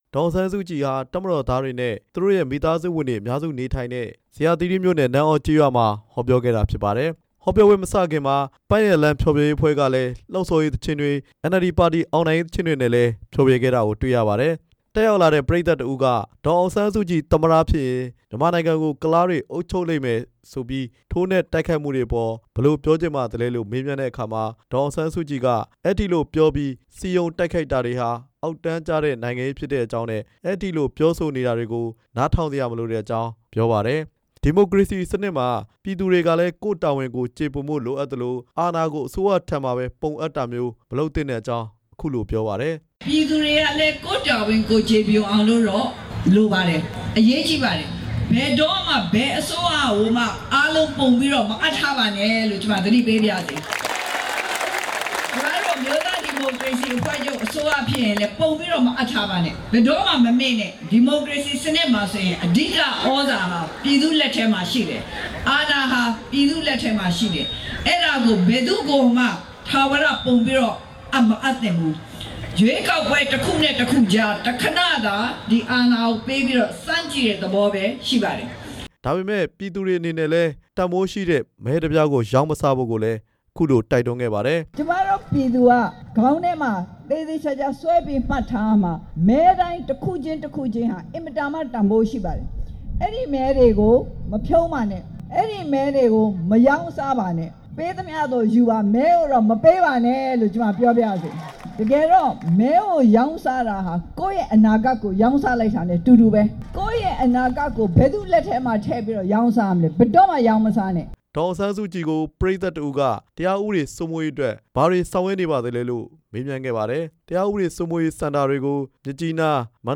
ဒေါ်အောင်ဆန်းစုကြည်ရဲ့ ဇေယျာသီရိမြို့နယ် ဟောပြောပွဲ
အမျိုးသားဒီမိုကရေစီအဖွဲ့ချုပ် ဥက္ကဌ ဒေါ်အောင်ဆန်းစုကြည်ဟာ ဒီကနေ့ နေပြည်တော်ကောင်စီ နယ်မြေ၊ ဇေယျာသီရိမြို့နယ်မှာ ရွေးကောက်ပွဲအသိပညာပေး ဟောပြောပွဲပြုလုပ်ခဲ့ပါတယ်။